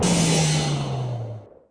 Shield Shutdown.mp3